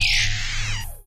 Electronic
Electro Close